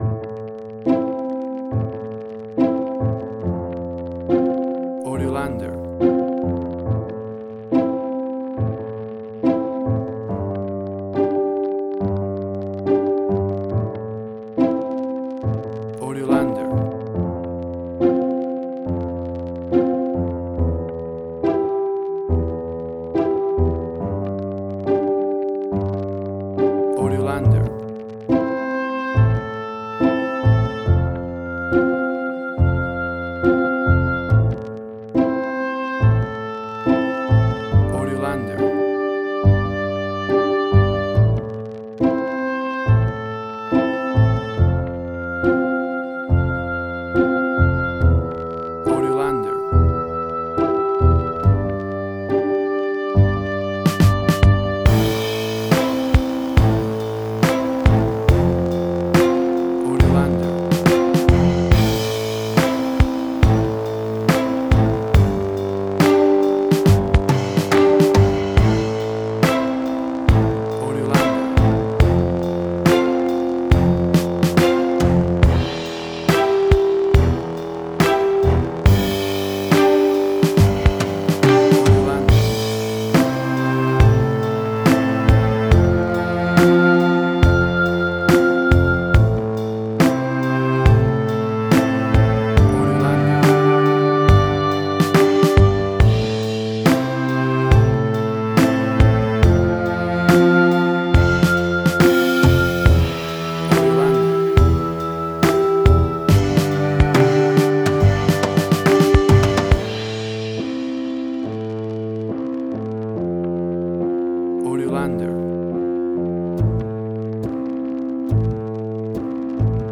Steampunk Sci-fi.
Tempo (BPM): 68